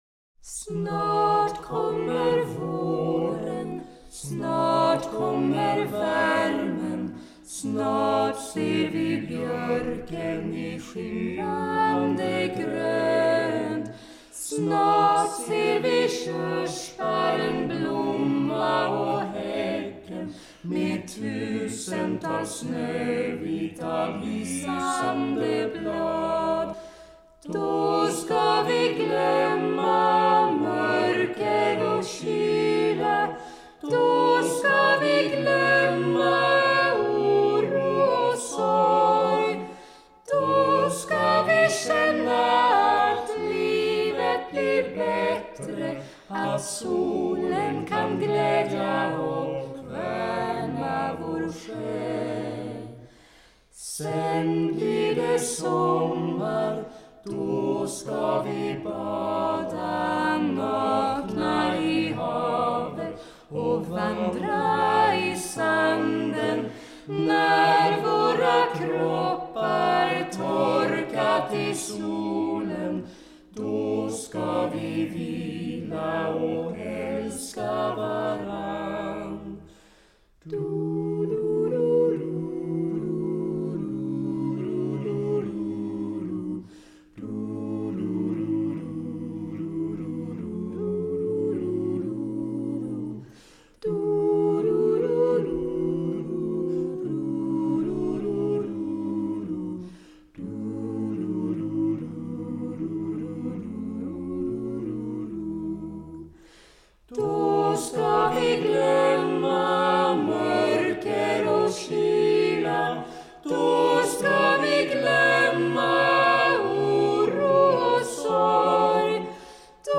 sång